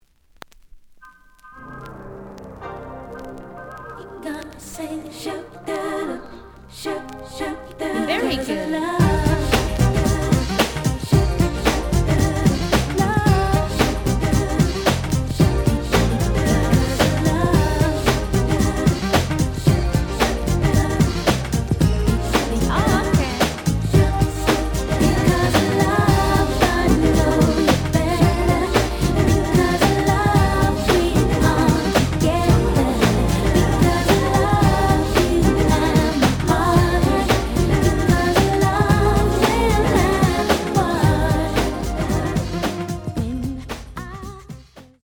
●Genre: Hip Hop / R&B